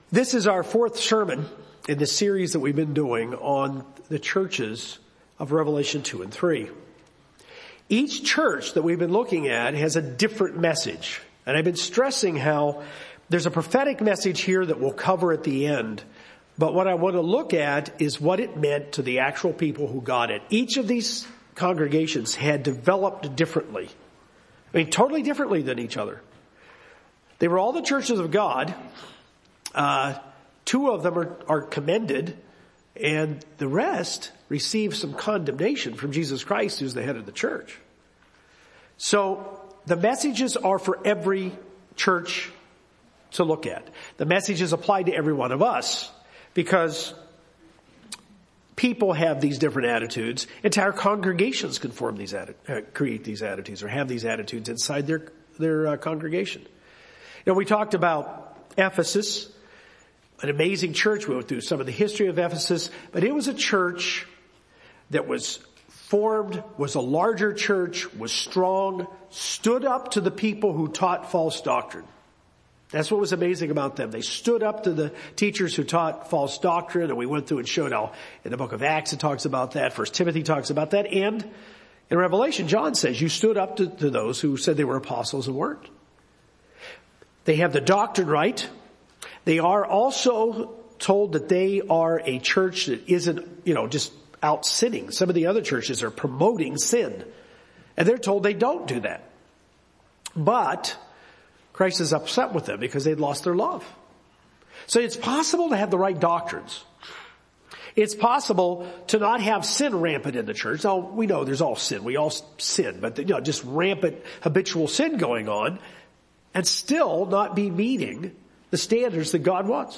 Jesus warns the church in Thyatira against the corruption of a woman, Jezebel, whose teachings have caused some members there to be sexually immoral and engaged in other pagan practices. This sermon explores traits that create a corrupt church and their direct implication for us today.